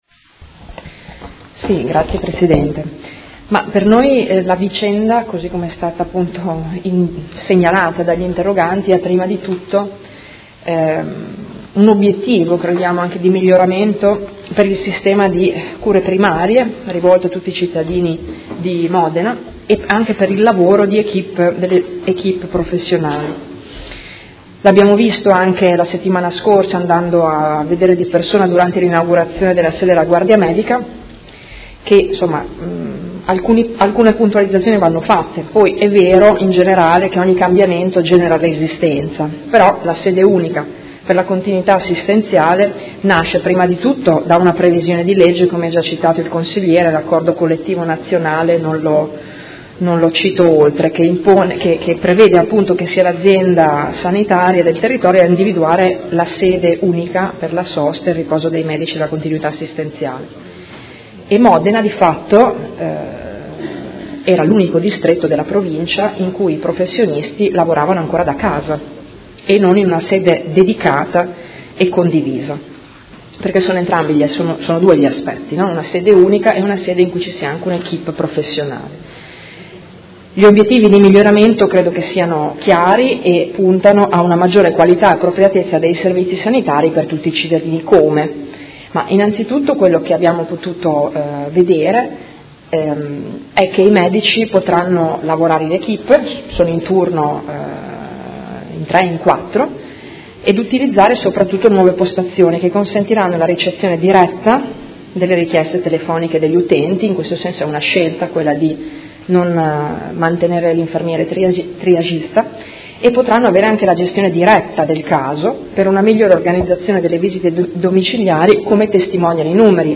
Seduta del 15/06/2017. Risponde a interrogazione dei Consiglieri Malferrari e Cugusi (Art.1-MDP) avente per oggetto: Contestazioni disciplinari dell’AUSL di Modena a medici del servizio di continuità assistenziale